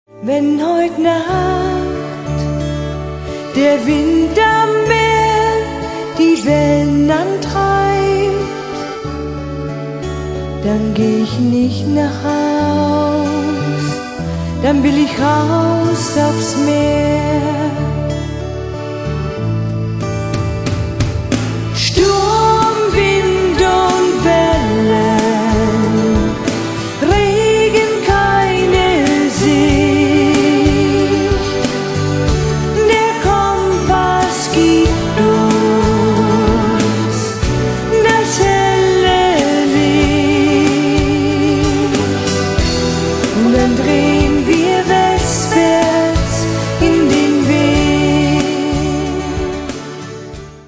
rhythmischen Beats.
Die ausdrucksvolle Stimme
Rock-Folklorestil eine